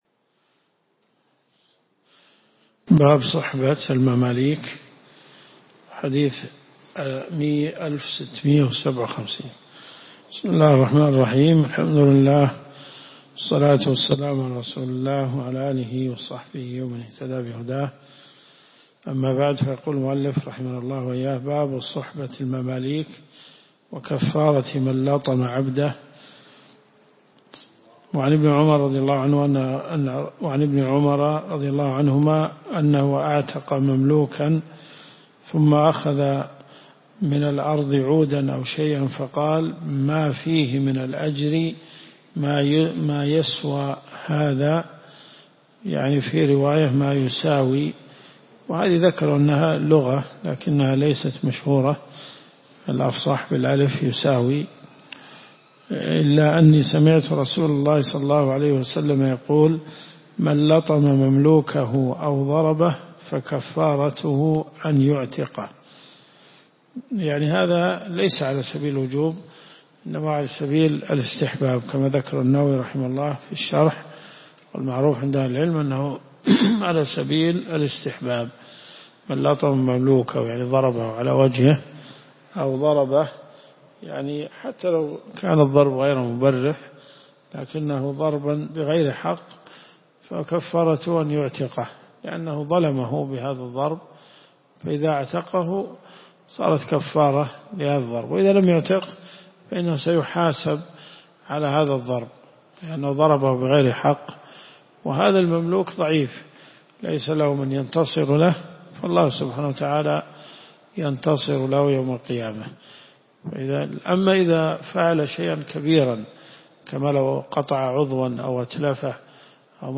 الرئيسية الكتب المسموعة [ قسم الحديث ] > صحيح مسلم .